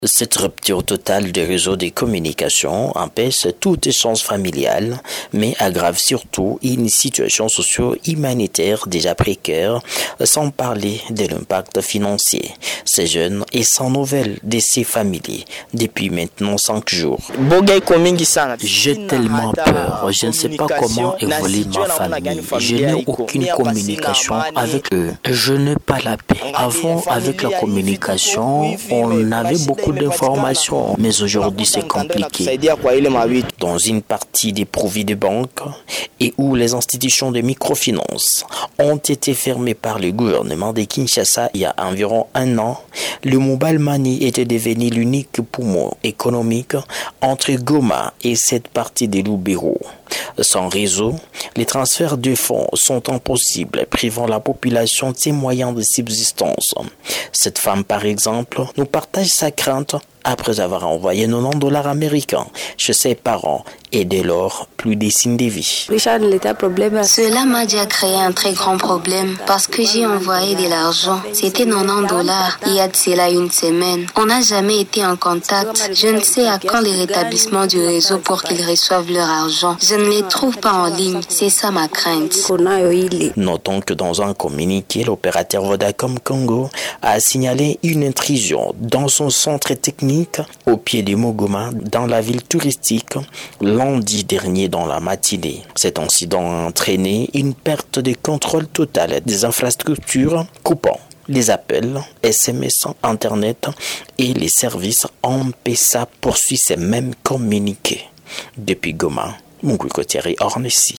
Un jeune homme confie son désarroi : « J’ai tellement peur, je ne sais pas comment évolue ma famille depuis maintenant 5 jour.